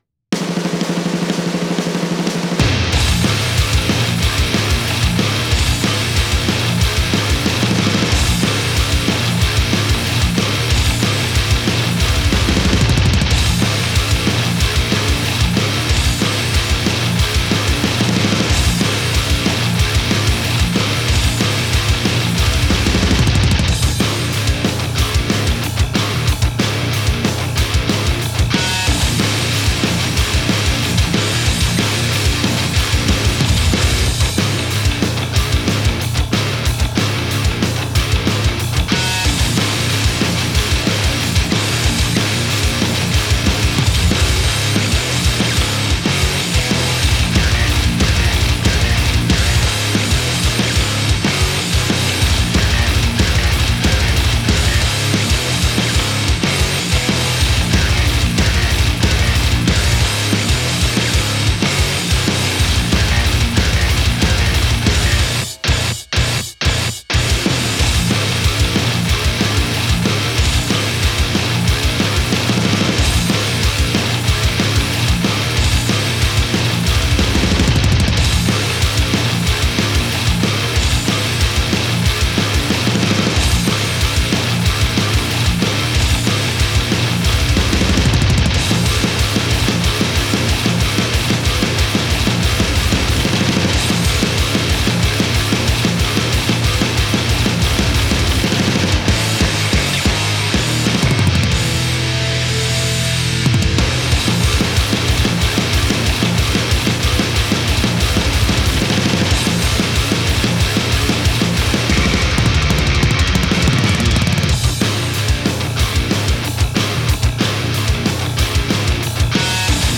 スピード感のあるゴリっとした